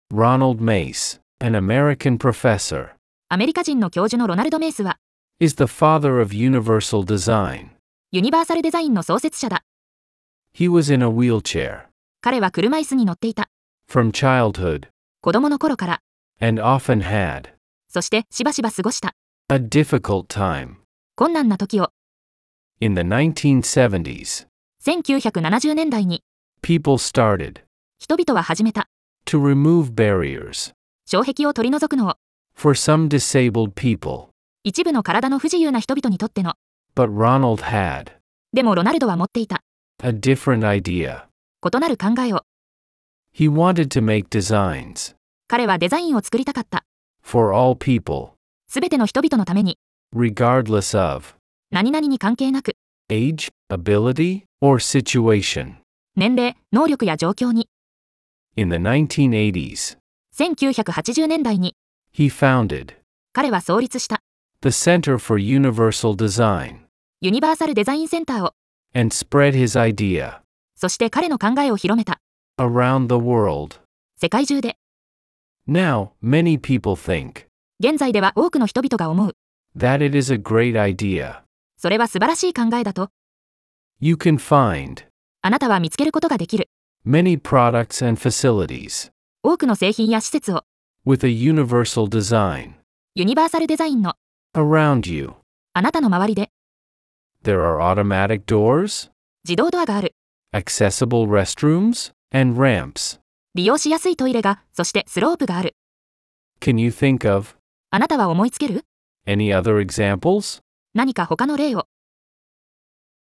♪ 習得用の音声(英⇒日を区切りごと)：